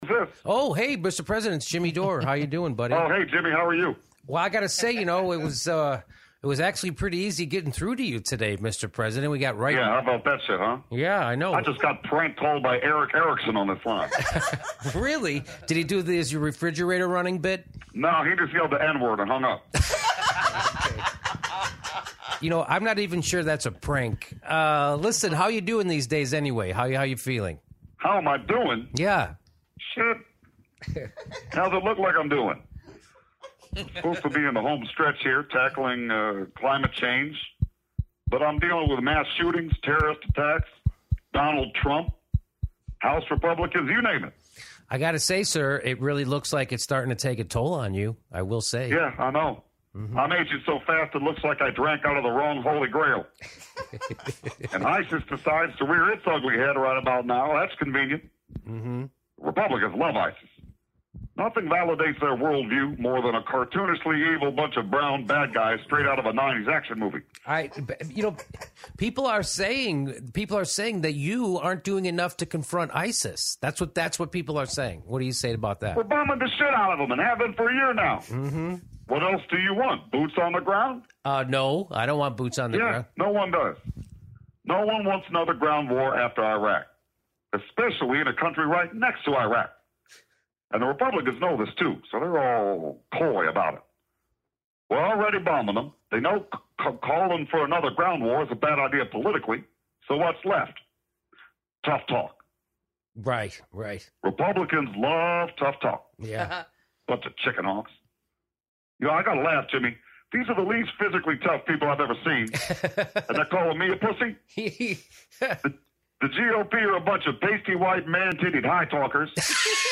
Listen to an explanation how ridiculous that is: Warning EXPLICIT language